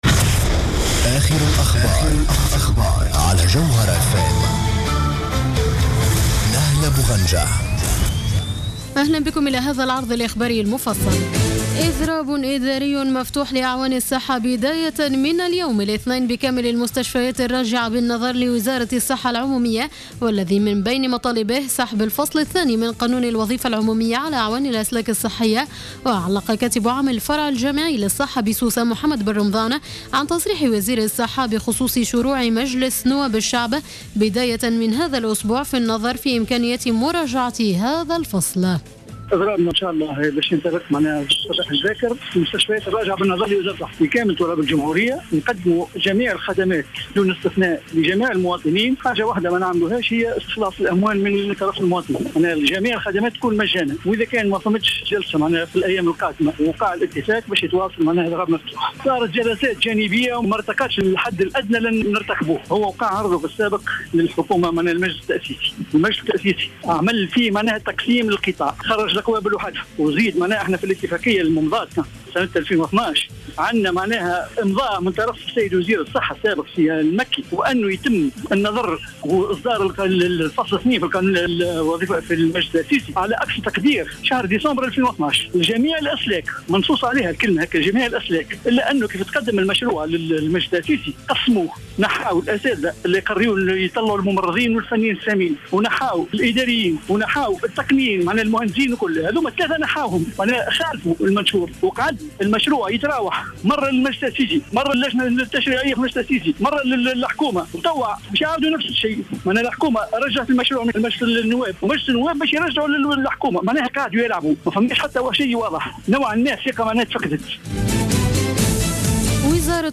نشرة أخبار منتصف الليل ليوم الإثنين 25 ماي 2015